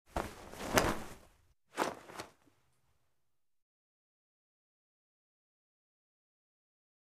Cloth, Flap | Sneak On The Lot